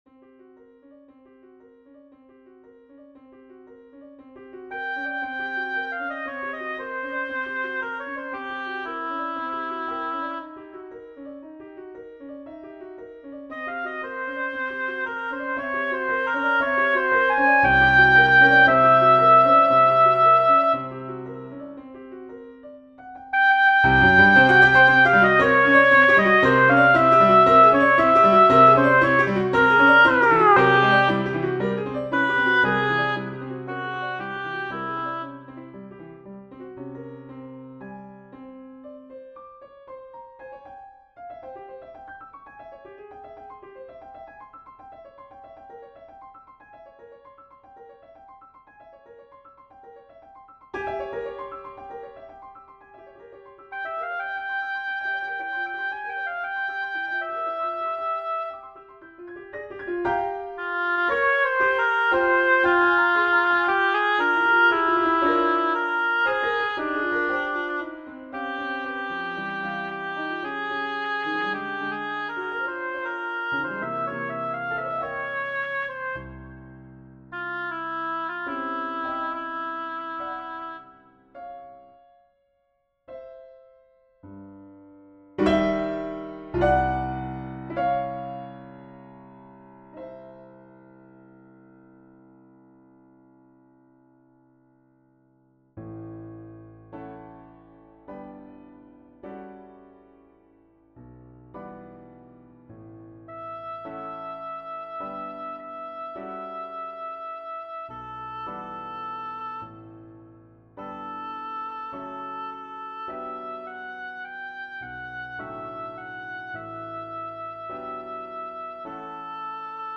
This is the third movement of a song cycle that I wrote from January to April of last year (2025). Each of the five movements is a setting of a different poem by Sara Teasdale, and together create a suite of nocturnes.